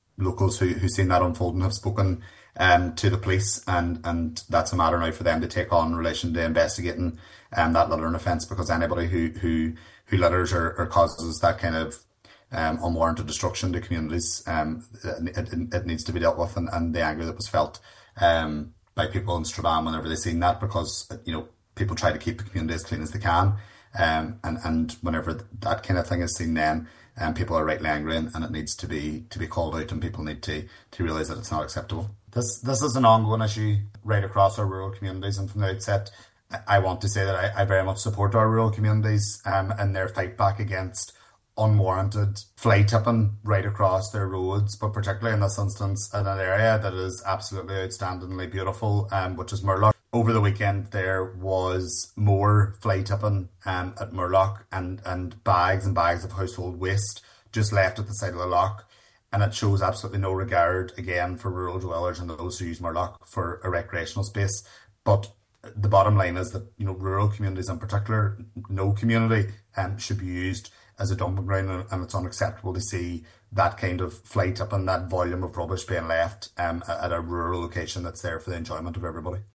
Local Councillor Paul Boggs has confirmed the case has been forwarded to the PSNI.